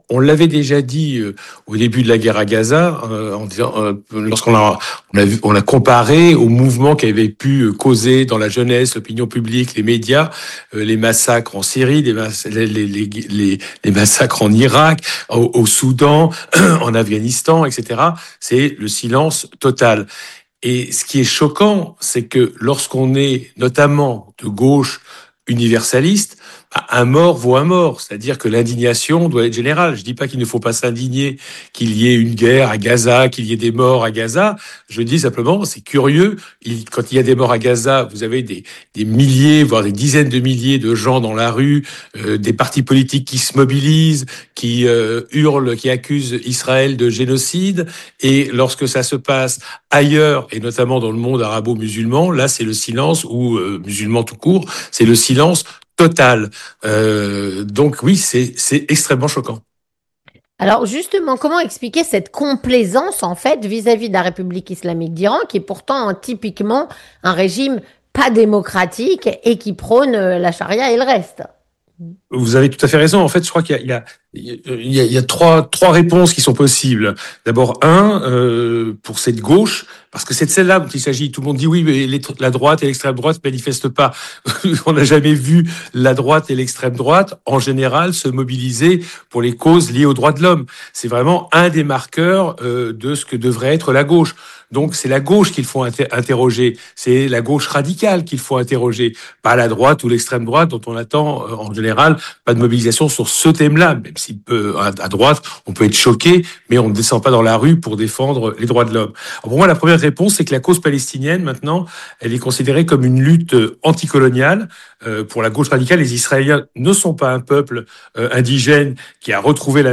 Jean Quatremer Journaliste, écrivain et correspondant à Bruxelles de nos confrères français du journal “Libération”, répond à la “Question Du Jour”.